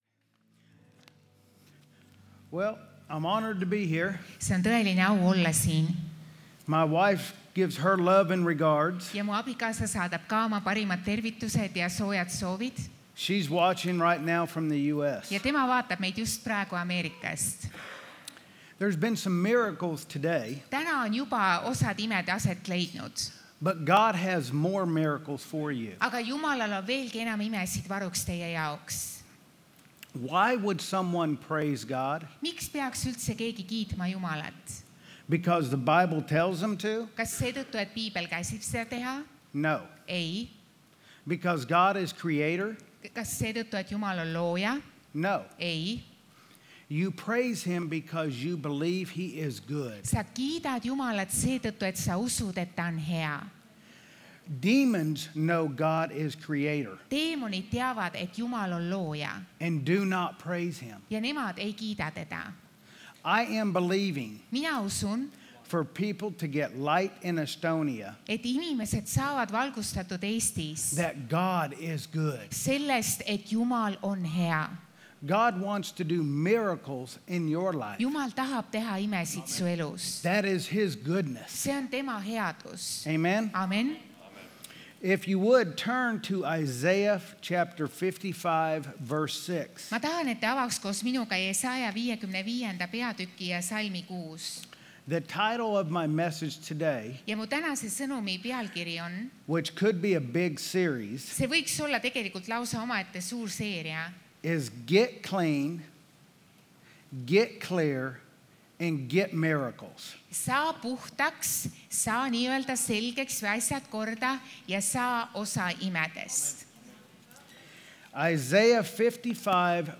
Jutlused - EKNK Toompea kogudus
Kristlik ja kaasaegne kogudus Tallinna kesklinnas.